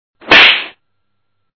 1 channel
13_hit.mp3